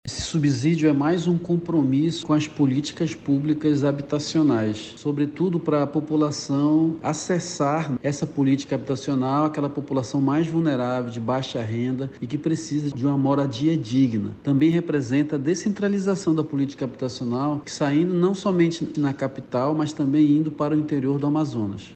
O secretário Marcellus Campêlo, da Unidade Gestora de Projetos Especiais – UGPE, explica que o programa é voltado para famílias de baixa renda do interior do Amazonas.
SONORA-SUBSDIOS-AMAZONAS-MEU-LAR-.mp3